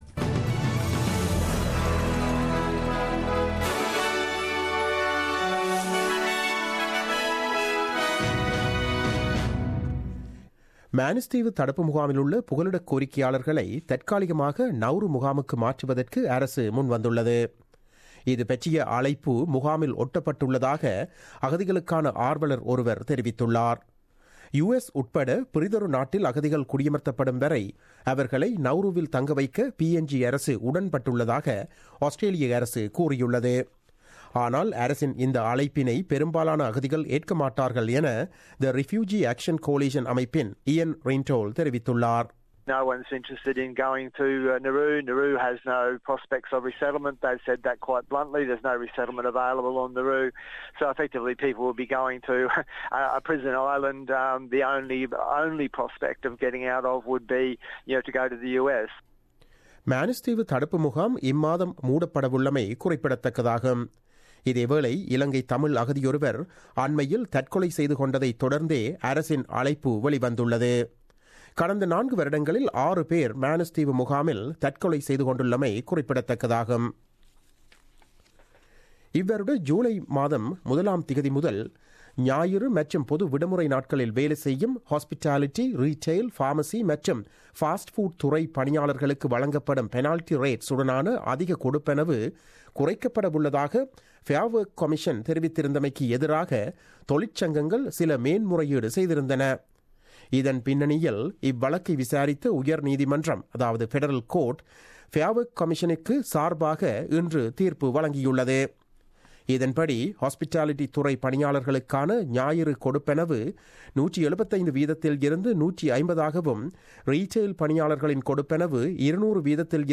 The news bulletin broadcasted on 11 October 2017 at 8pm.